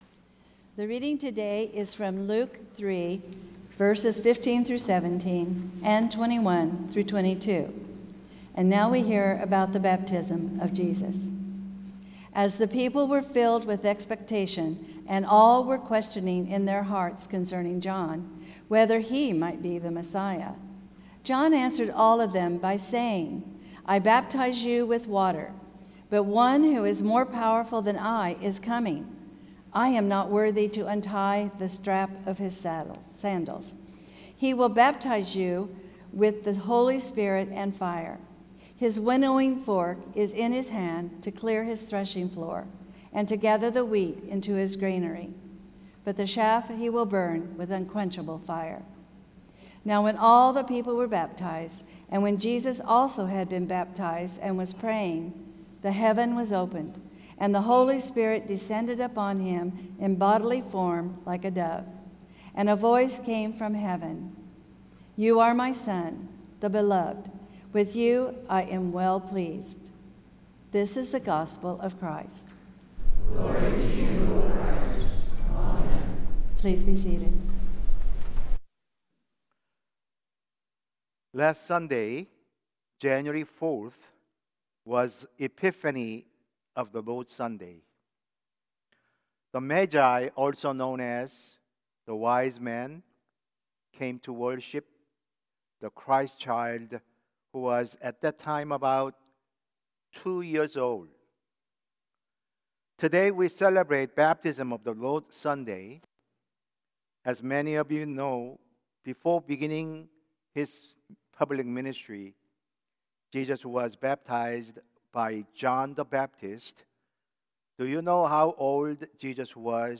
Sermon – Methodist Church Riverside